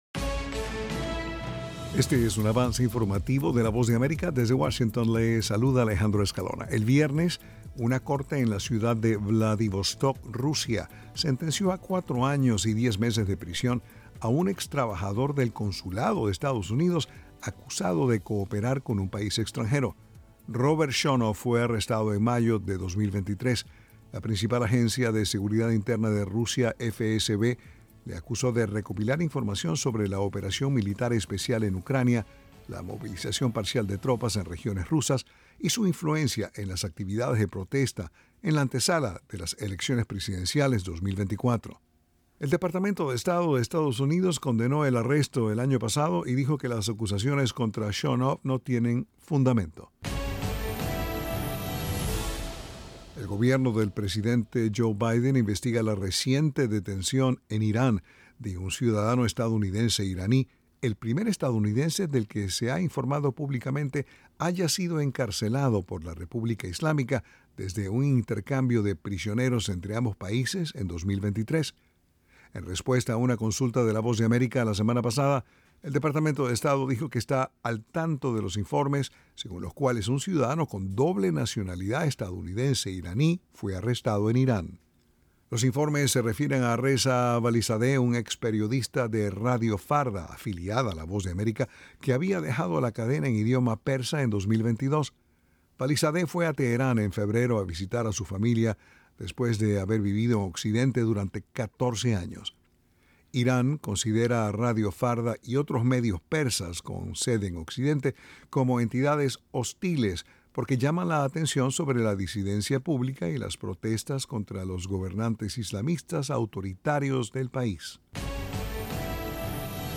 Avance Informativo
Este es un avance informativo de la Voz de América en Washington.